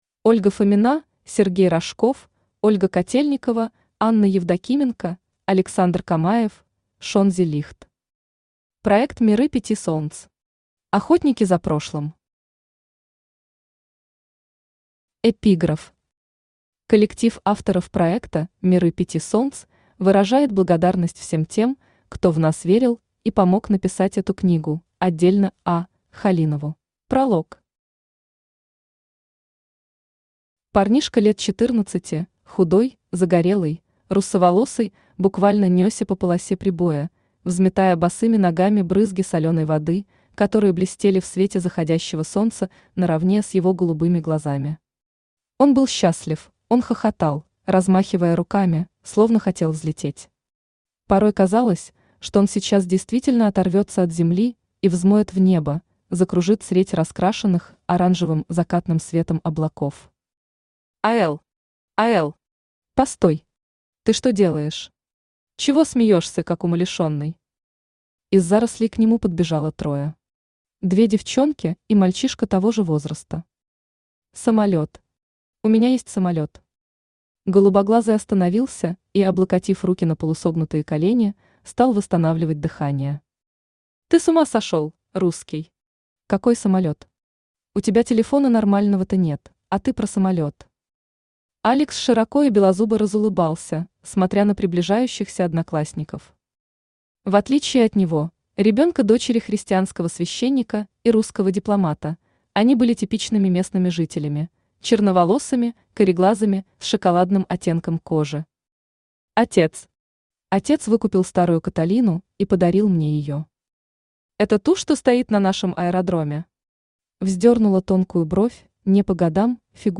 Аудиокнига Проект «Миры пяти солнц». Охотники за прошлым | Библиотека аудиокниг
Охотники за прошлым Автор Ольга Фомина Читает аудиокнигу Авточтец ЛитРес.